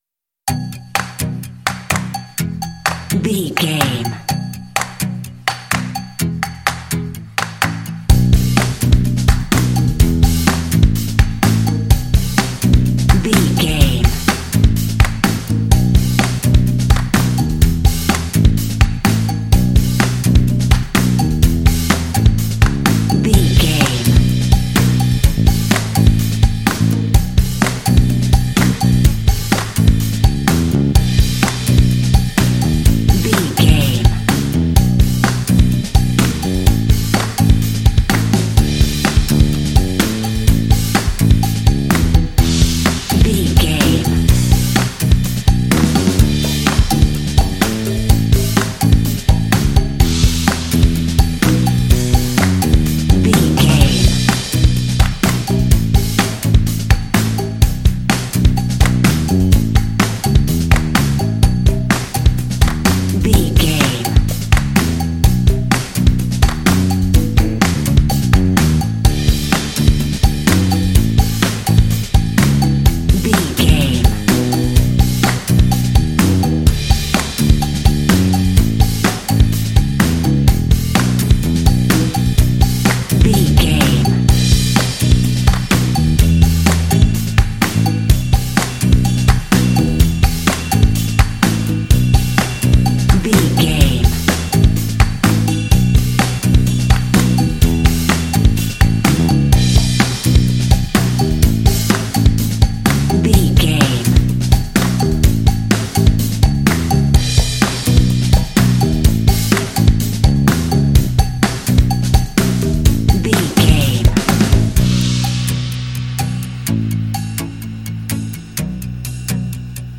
This fun and lighthearted track features a funky organ.
Uplifting
Mixolydian
bouncy
electric guitar
drums
organ
bass guitar
rock
blues